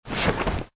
window_open.ogg